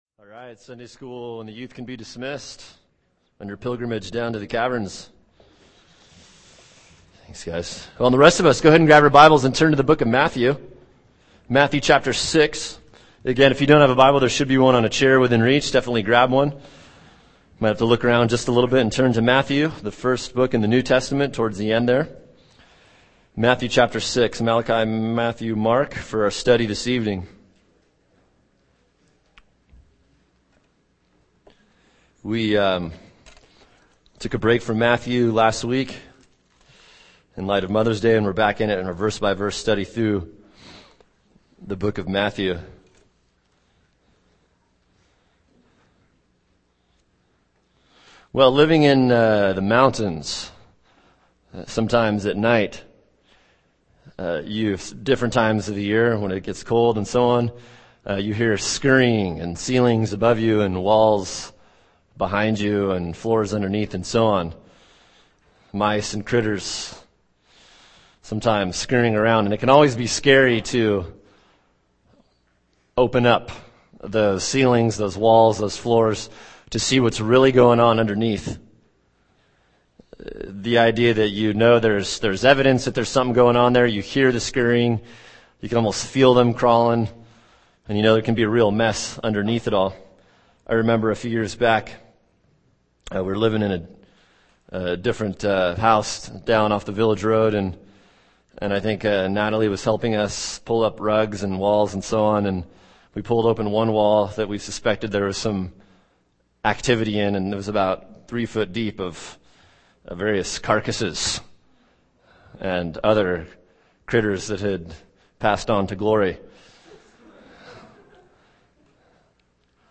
[sermon] Matthew 6:1 “Religiatrics” | Cornerstone Church - Jackson Hole